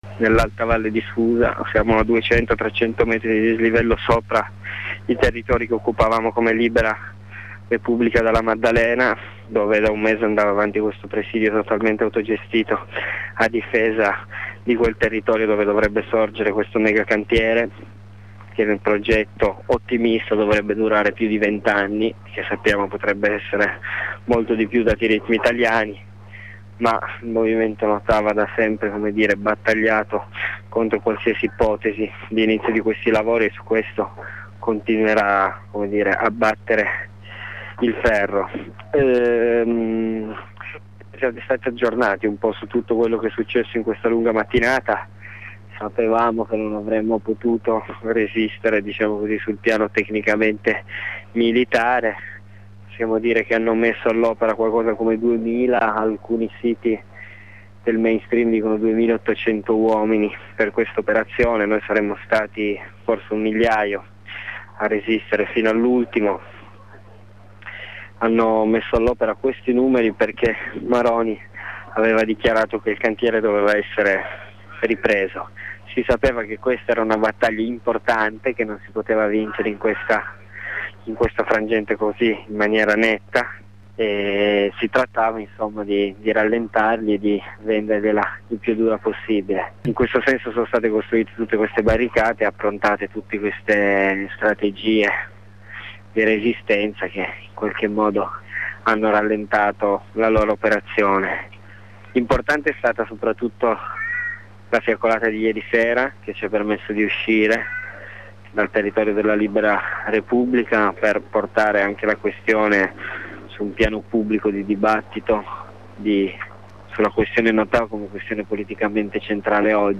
Corrispondenza delle ore 11:20 con un redattore di Radio Blackout. Una parte dei manifestanti si trova a Sant'Ambrogio, al di sopra di quella che era la libera Repubblica della Maddalena, distrutta dal violento attacco dello Stato.